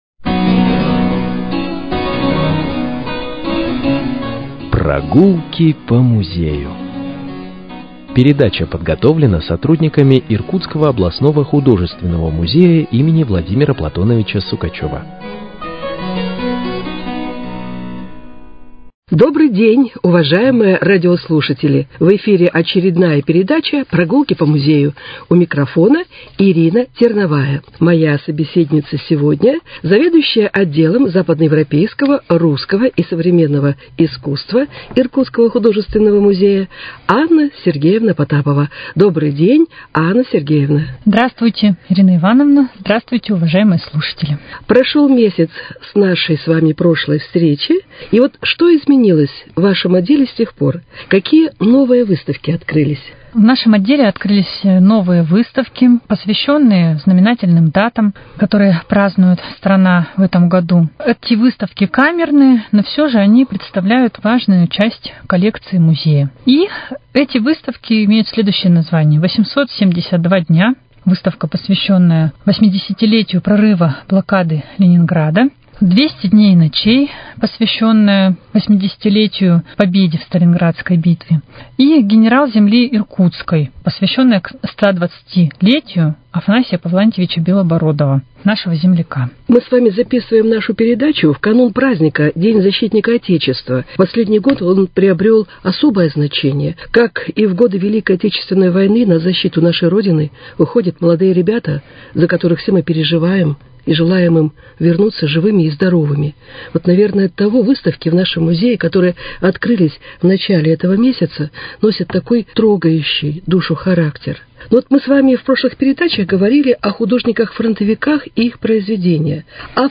Прогулки по музею: Беседа с заведующей Отделом западно-европейского